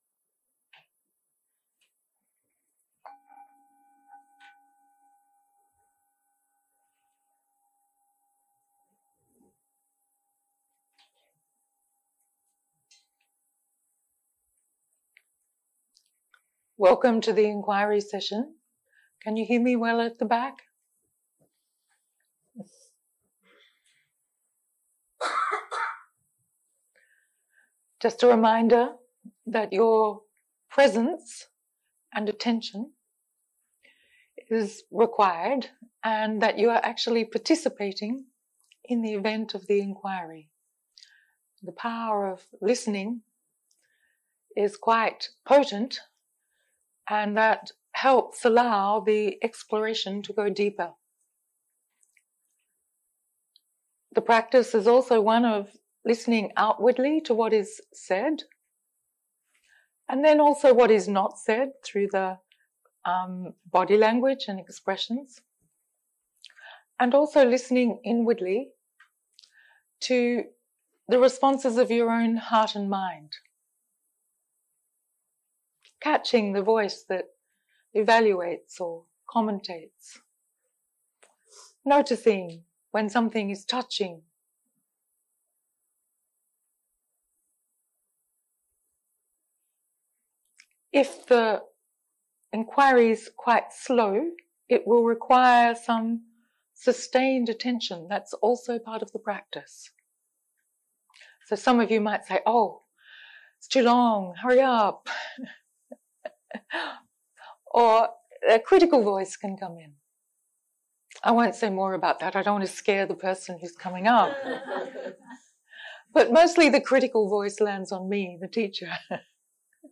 סוג ההקלטה: חקירה